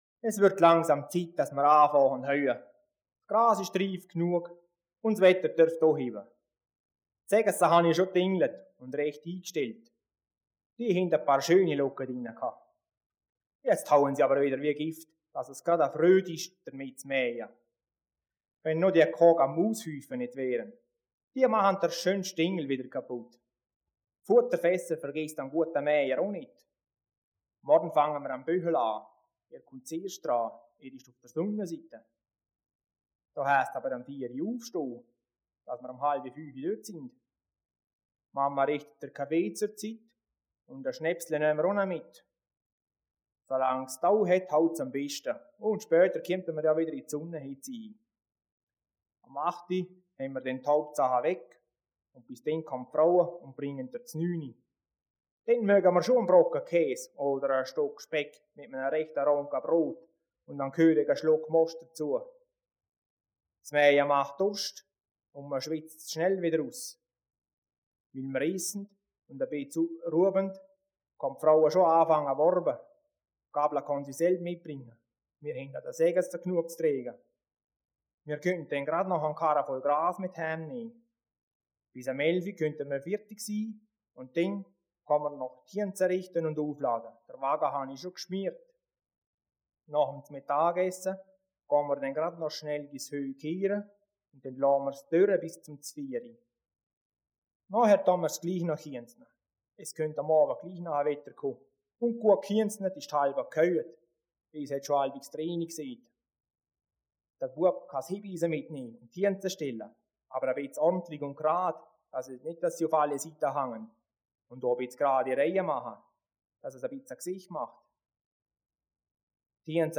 Liechtensteiner Mundarten 1960 (Gemeinden)
Geschichten in Mundart